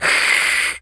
cat_hiss_01.wav